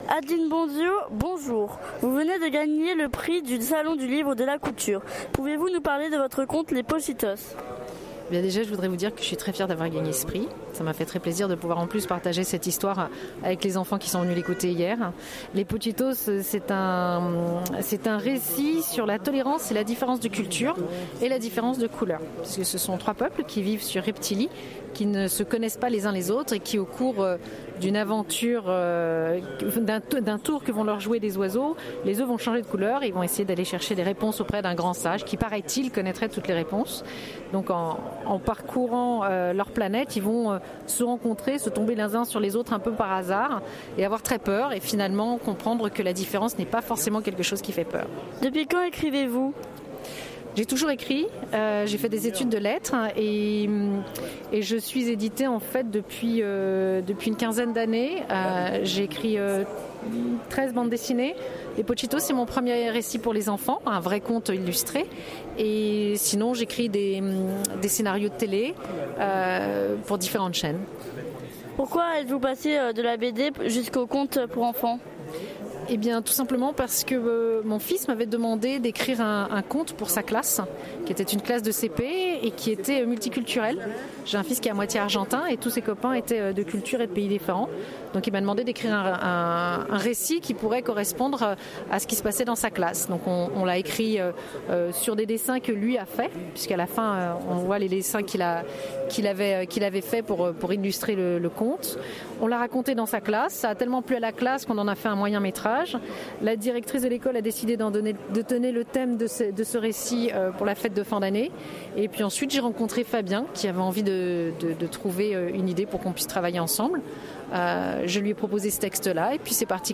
Atelier radio et atelier dessin samedi après-midi et dimanche après-midi
VOICI LES REPORTAGES